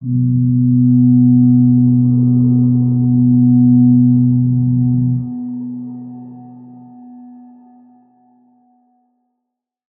G_Crystal-B3-mf.wav